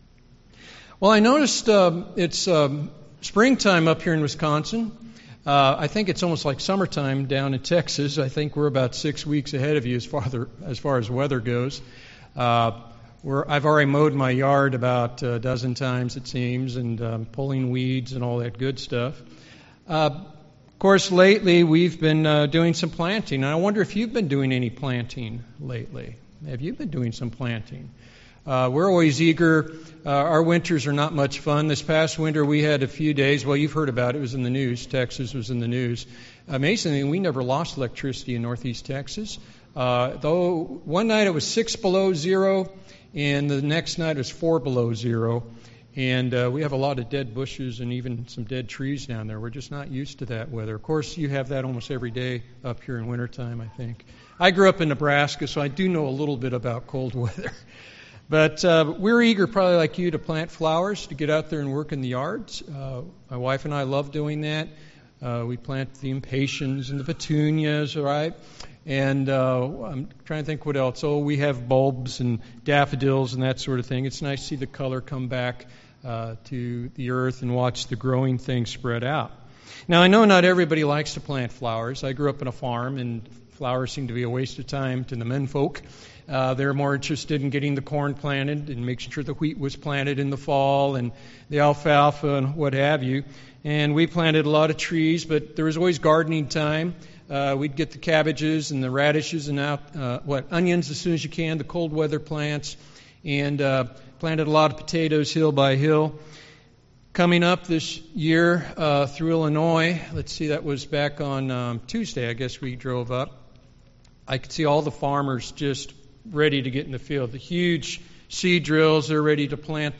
In today’s sermon we will consider these two ways of life and so be reminded to keep sowing to the Spirit.